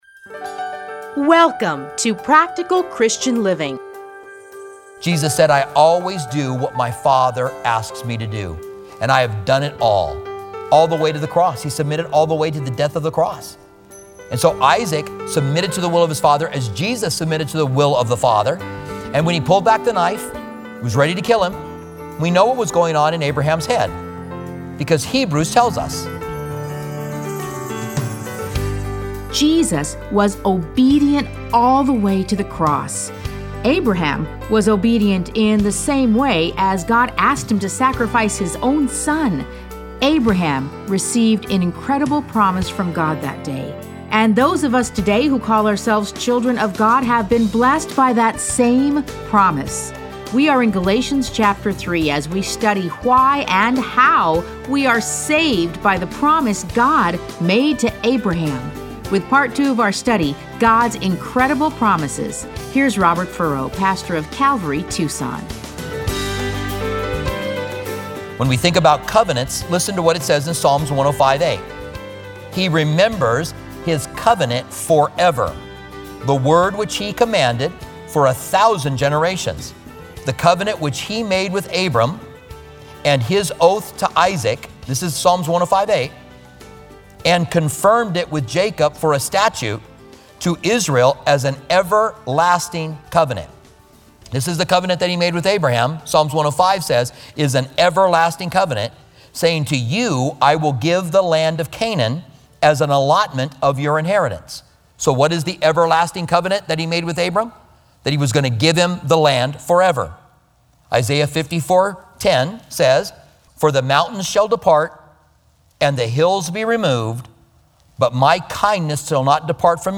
Listen to a teaching from Galatians 3:15-18.